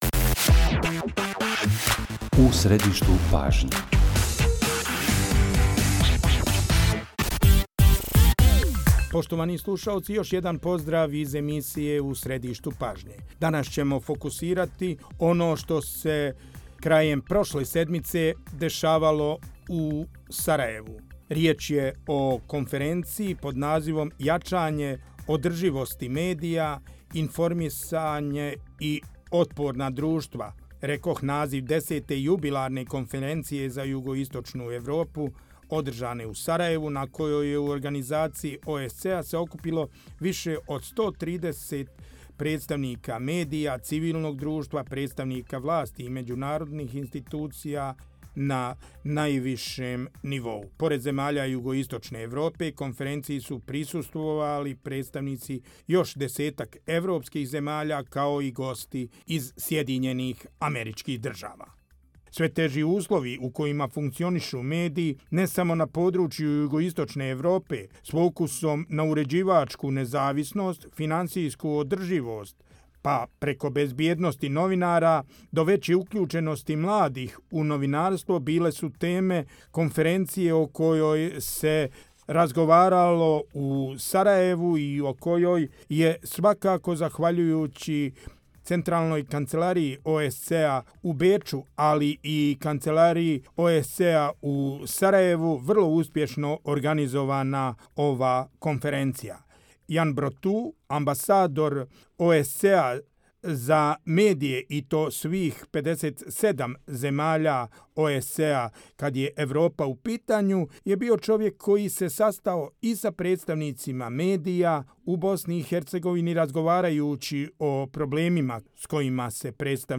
U emisiji smo razgovarali sa ključnim akterima konferencije koja je okupila više od 130 novinara, predstavnika nevladinog sektora i čelnika političkog života iz gotovo cijelke Evrope, kao i goste iz SAD-a.